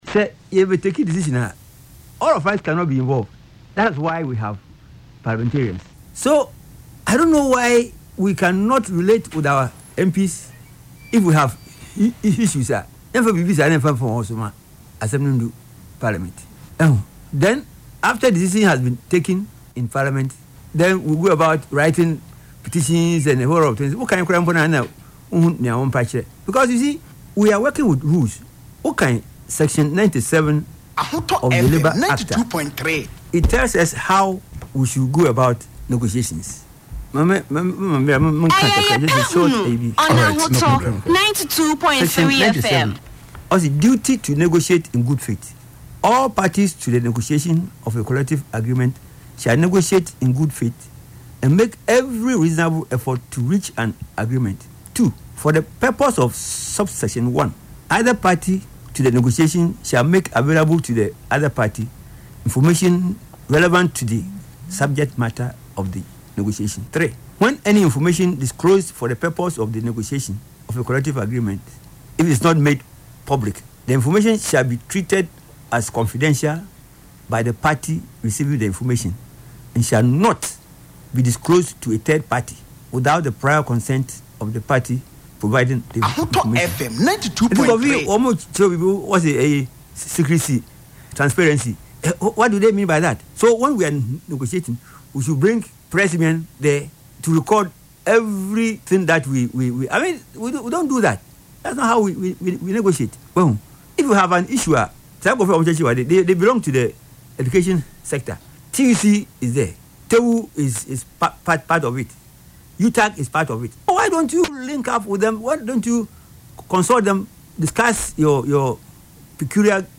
Reacting to the controversy on Ahotor FM’s “Yepe Ahunu” show on Saturday